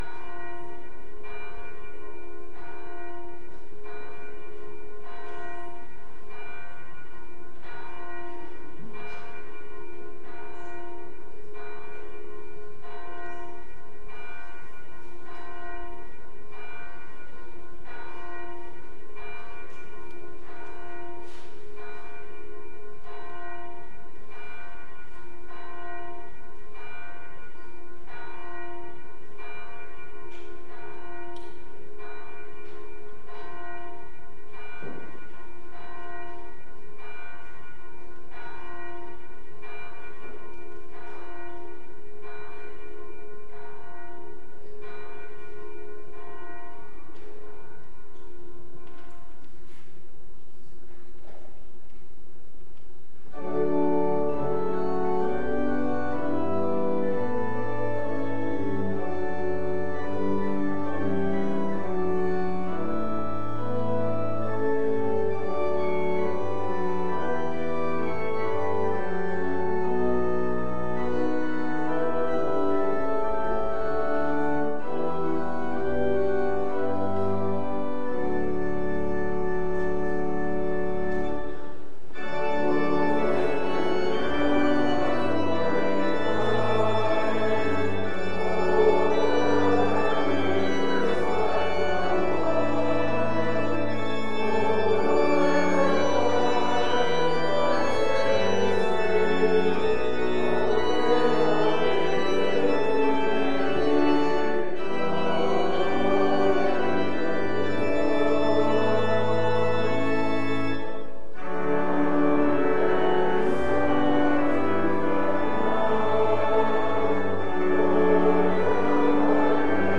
LCOS Worship Service